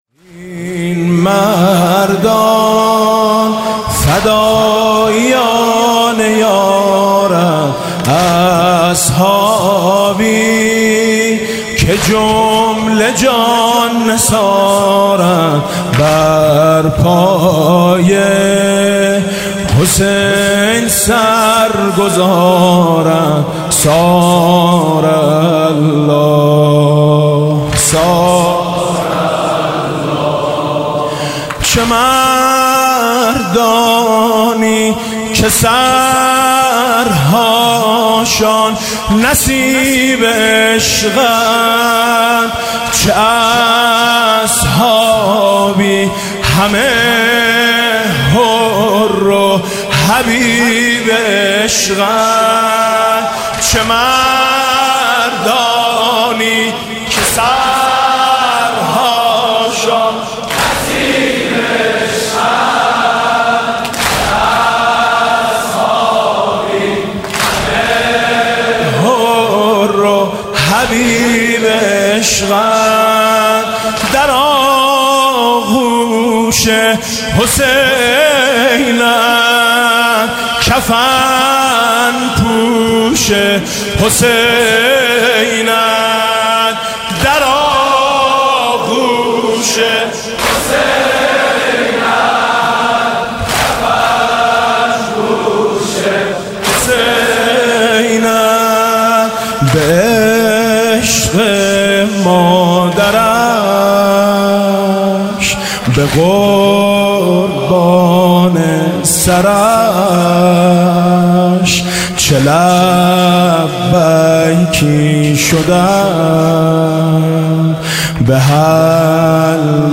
شب چهارم محرم 95_واحد عربی_نریدُ أن نُعیدَ الیَوْمَ کربلائَک
محرم 95
میثم مطیعی محرم 95 واحد عربی